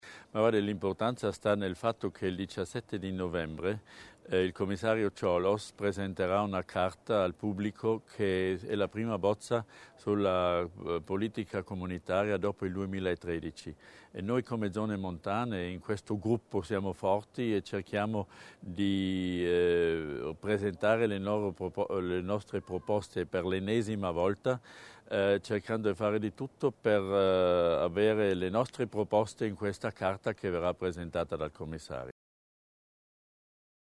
L'Assessore Berger sull'importanza dell'incontro